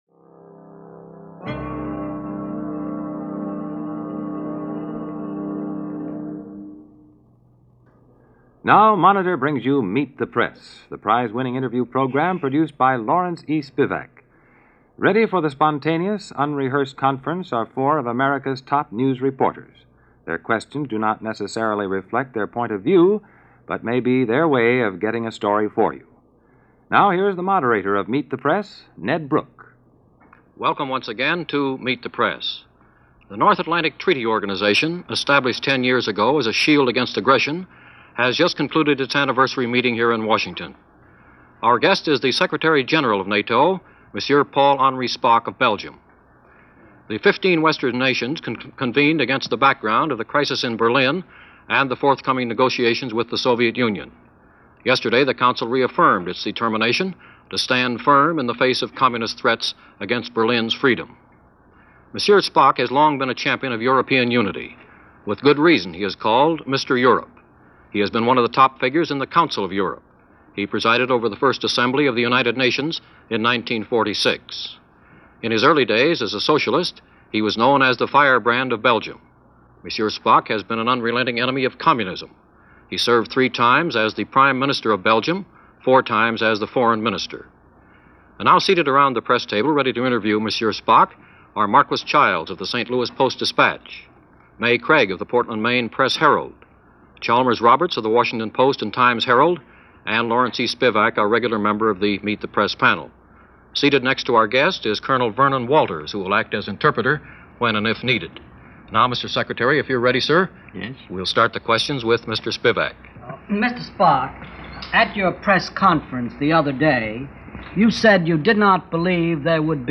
Paul Henri-Spaak - Father of the European Union - Interviewed on Meet The Press - April 5, 1959 - re: Berlin and the potential crisis between East and West.
But in this broadcast interview with Paul-Henri Spaak, Belgian politician and statesman, Secretary – General of NATO, the alliance formed in 1949 to combat what was considered to be an imposing presence of the Soviet Union in Europe, concerns were mounting over increased pressure and friction between East and West, which many feared would lead to an armed confrontation, and possibly worse.